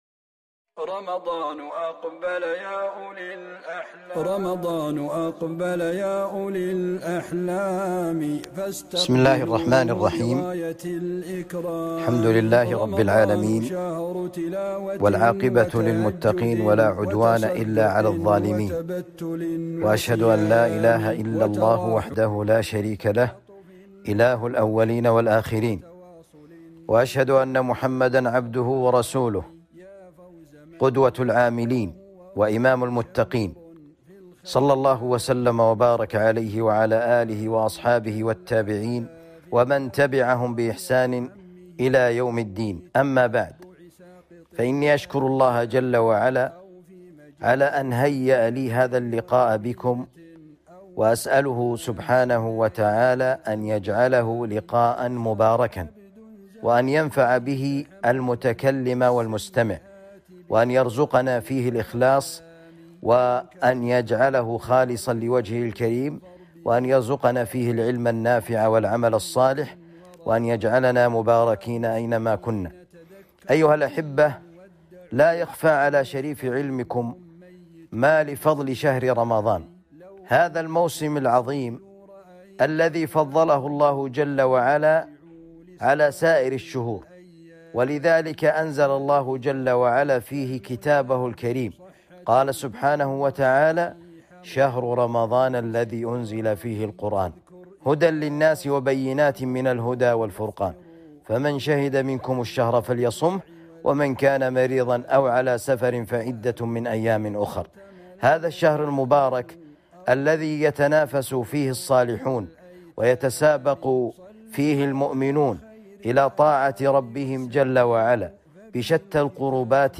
محاضرة بعنوان كيف نستقبل شهر رمضان